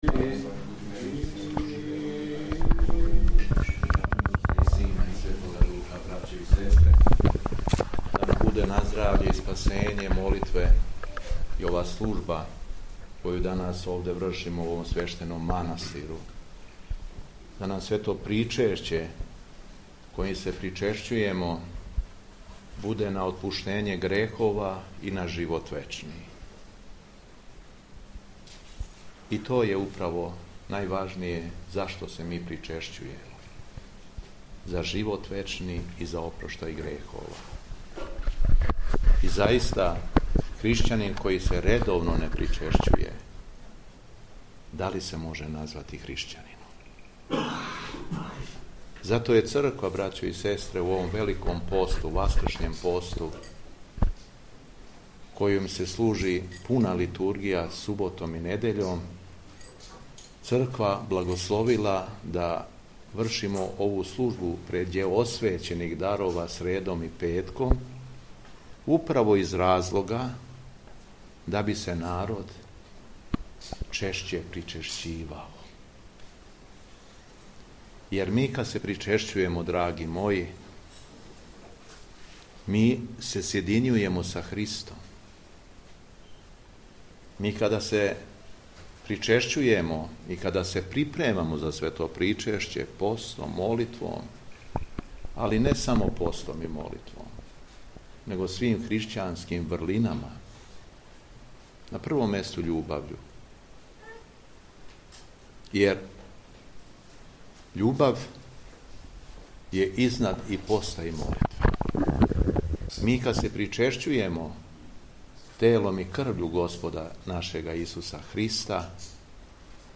У среду друге недеље Часног поста, 12. марта, Његово Високопреосвештенство Митрополит шумадијски Господин Јован служио је Литургију Пређеосвећених Дарова у манастиру Ћелије колубарске надомак Лазаревца.
Беседа Његовог Високопреосвештенства Митрополита шумадијског г. Јована